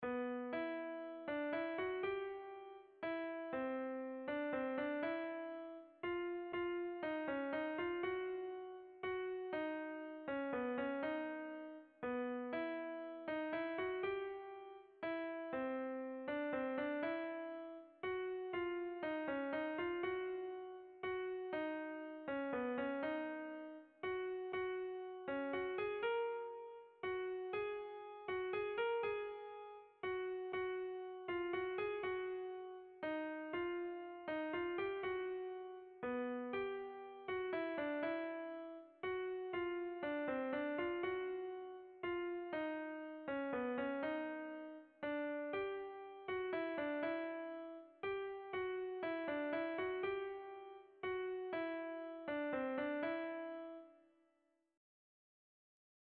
Kontakizunezkoa
ABABDEFF